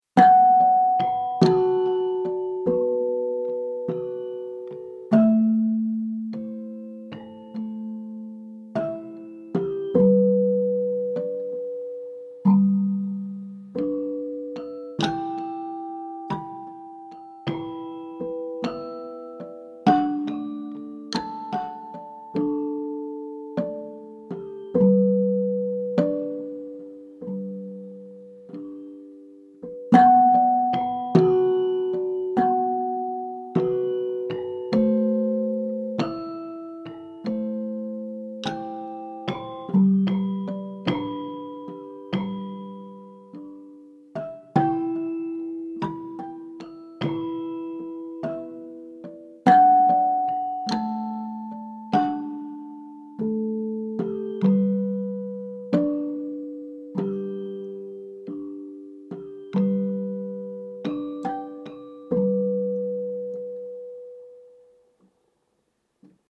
Minimal